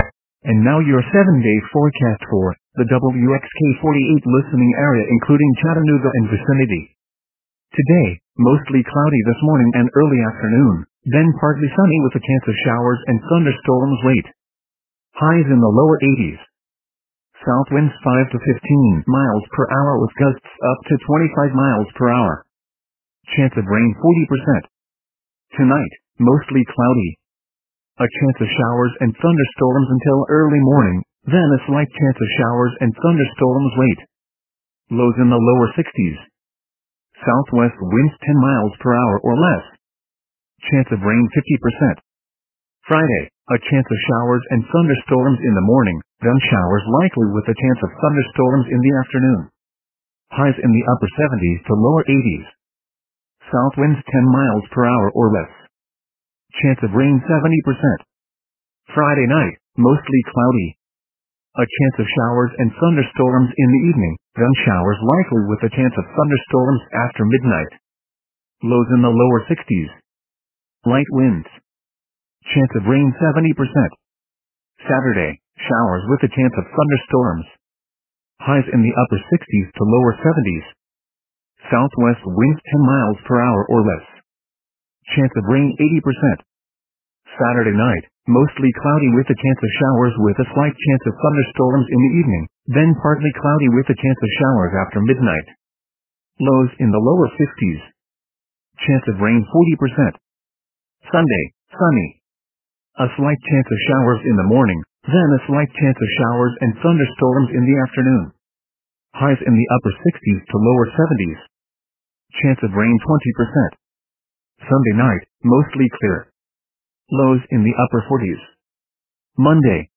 MRX Weather Radio Forecasts